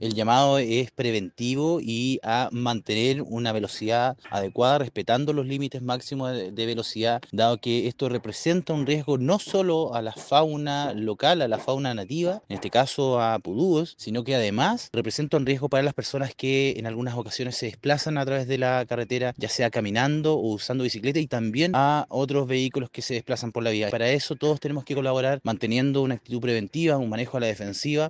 El Seremi de Tranportes de Los Lagos, Pablo Joost, también se refirió ante el comportamiento de los conductores e hizo un llamado a respetar los límites de velocidad.
cuna-seremi-joost.mp3